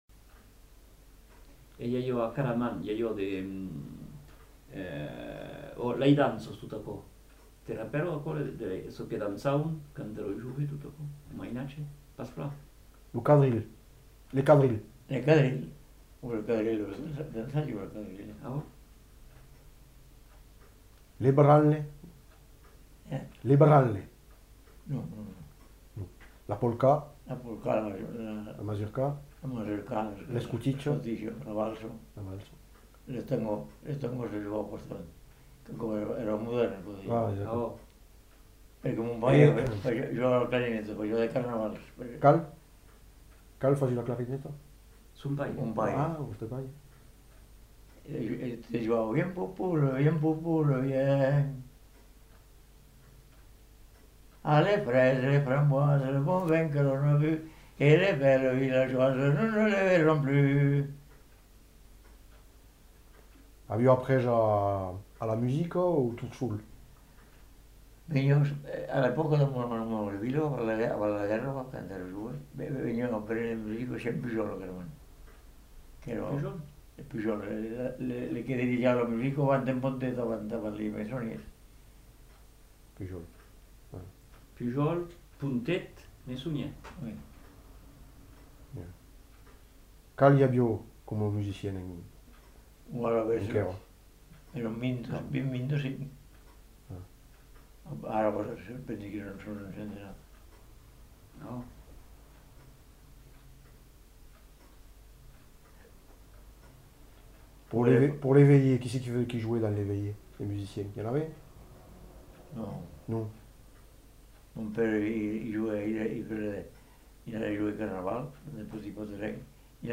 Aire culturelle : Lauragais
Genre : témoignage thématique